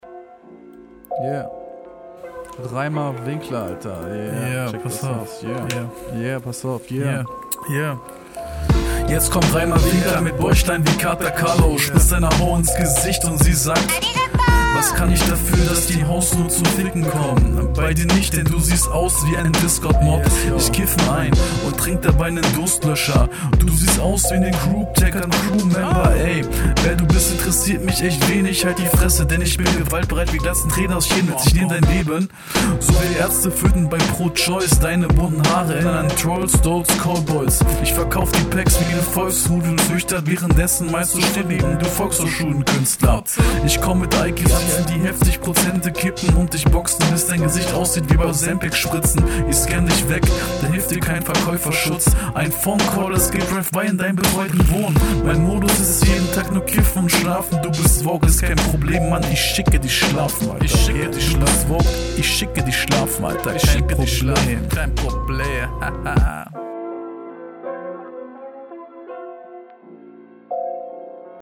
Cooler Beat und cooler style.